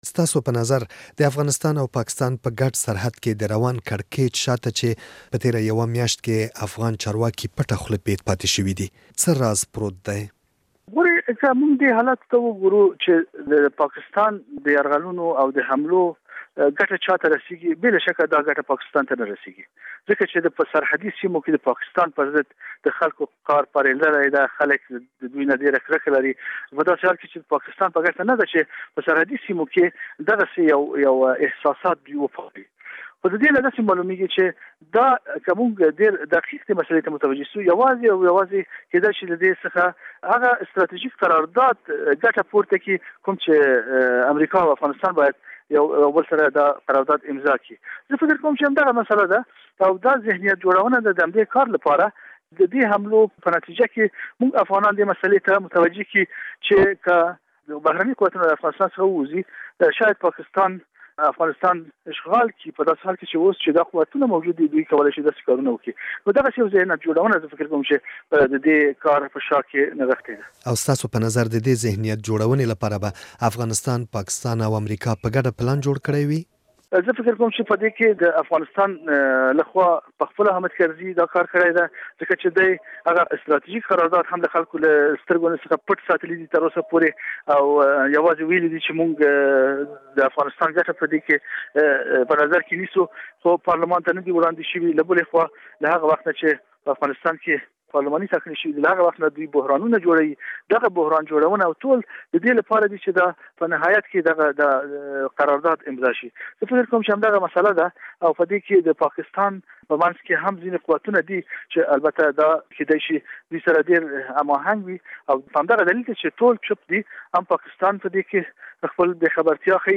وحيد مژده سره مرکه